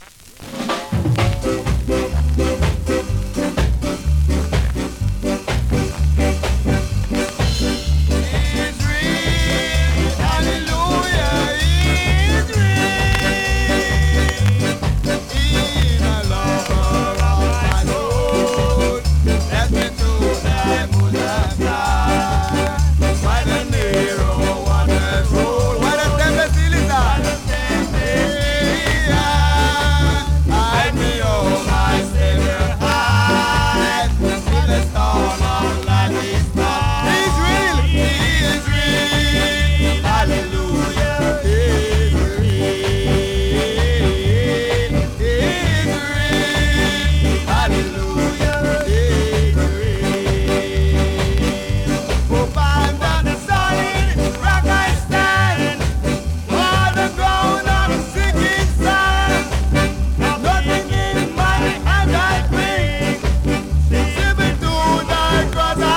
2026!! NEW IN!SKA〜REGGAE
スリキズ、ノイズそこそこありますが